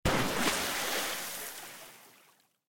explosion_water3.ogg